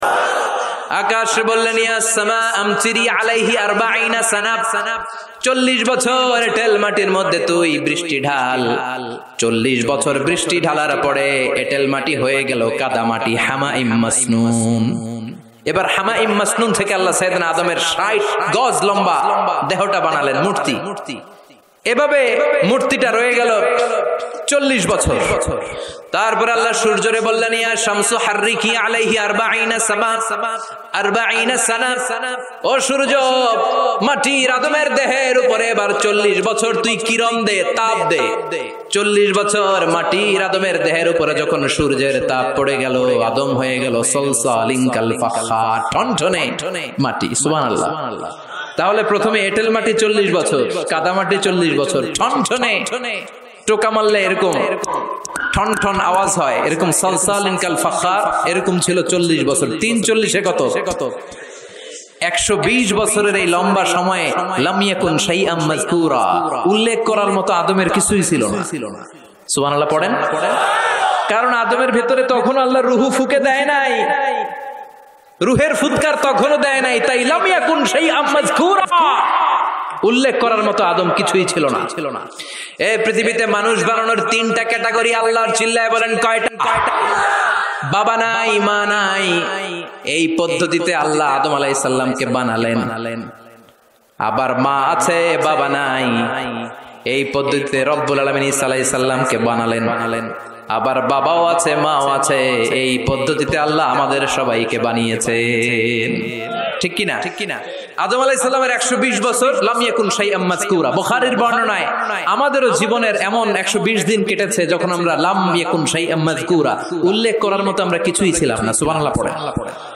bangla waz